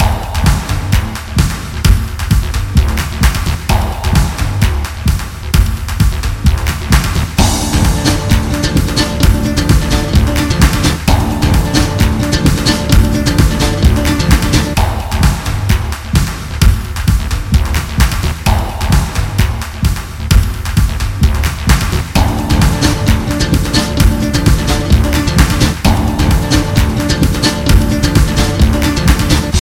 infos - rapide - urbain - stressant - actualite